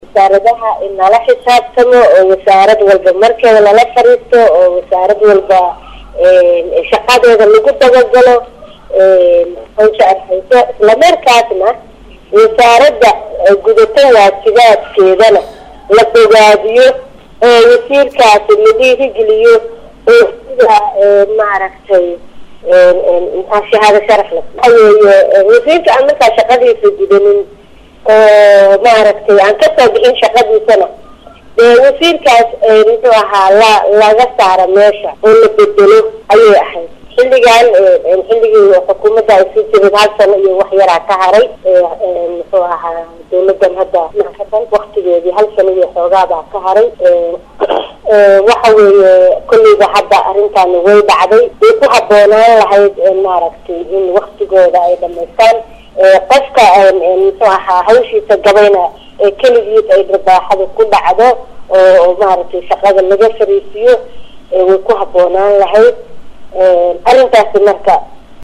Xildhibaanad Xaawooo la hadleeysay warbaahinta ayaa sheegtay in marka hore Xildhibaanada laga doonayay in ay mid mid ula xisaabtaan Xubnaha Golaha wasiirada ka hor inta aanay go aanka kala dirista qaadan.